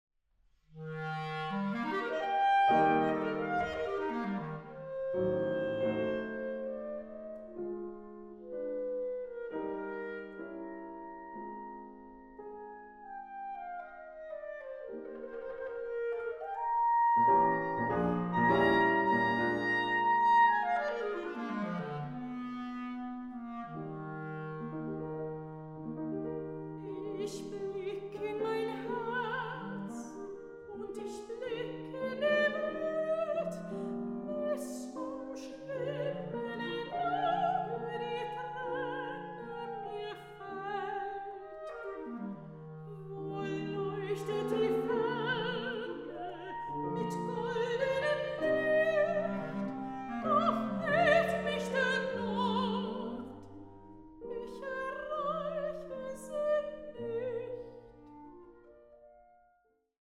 soprano
clarinet
piano